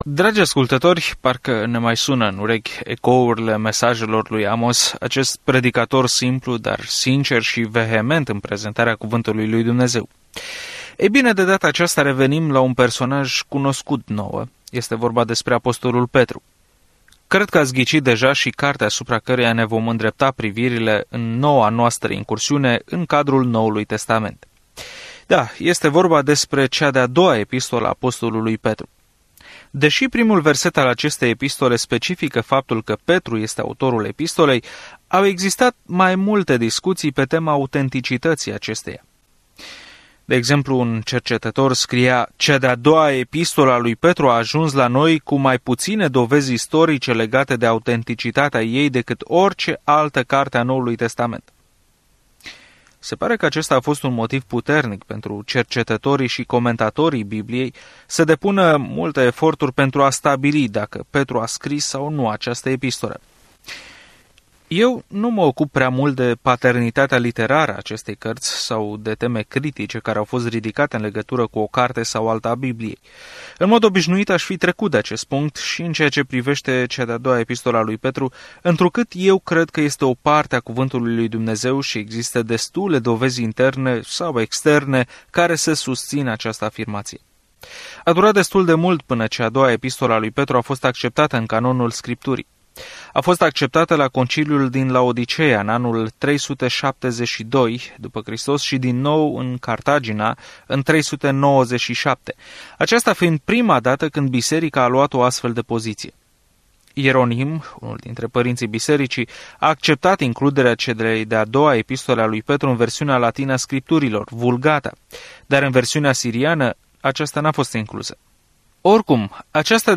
Scriptura 2 Petru 1:1-2 Începe acest plan Ziua 2 Despre acest plan A doua scrisoare a lui Petru este despre harul lui Dumnezeu - cum ne-a salvat, cum ne ține și cum putem trăi în ea - în ciuda a ceea ce spun profesorii falși. Călătoriți zilnic prin 2 Petru în timp ce ascultați studiul audio și citiți versete selectate din Cuvântul lui Dumnezeu.